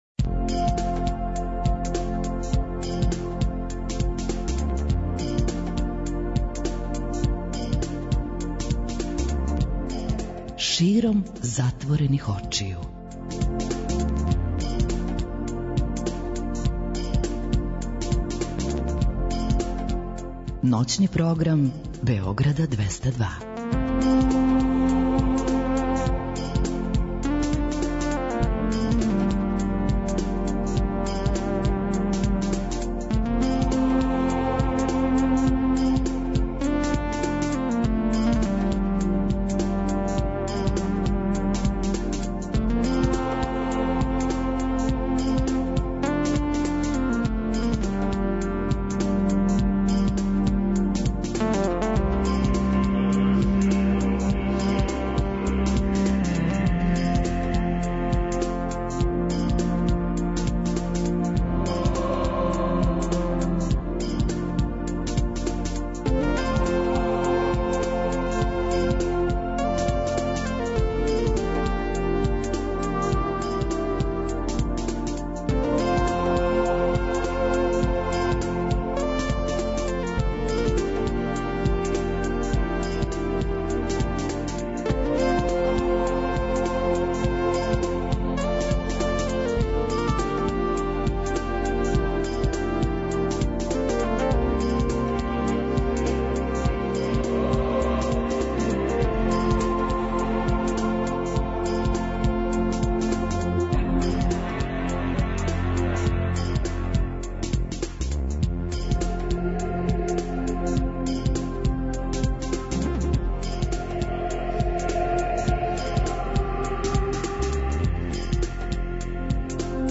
Ноћас емисија 'Златне године', музика 60-их и 70-тих.